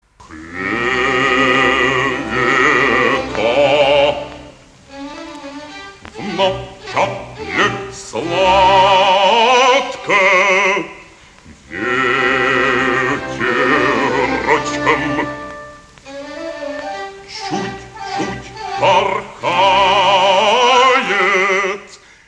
Кто поёт?